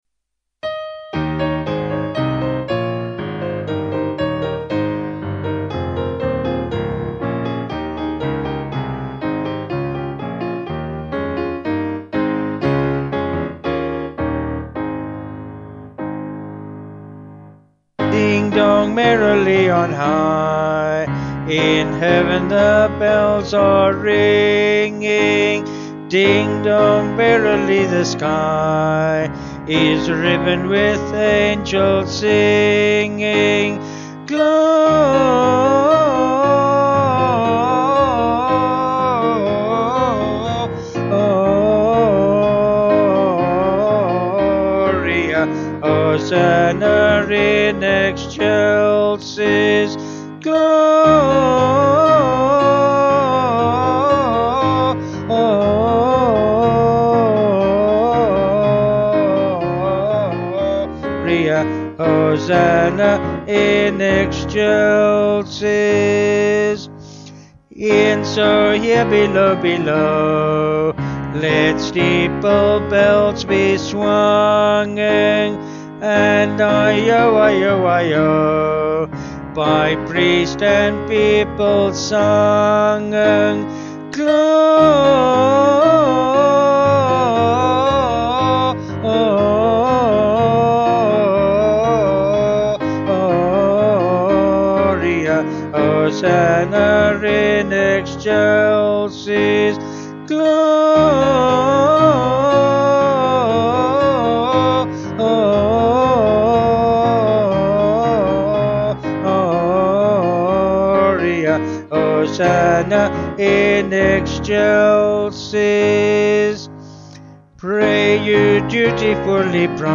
Vocals and Piano   321.8kb Sung Lyrics